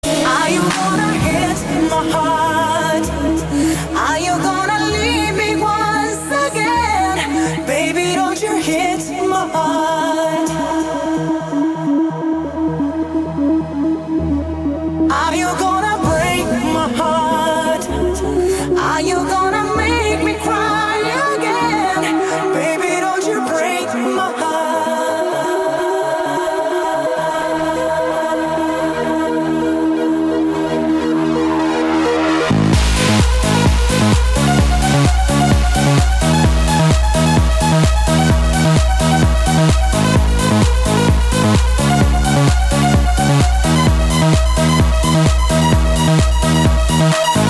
• Качество: 192, Stereo
женский вокал
dance
Electronic
club
electro house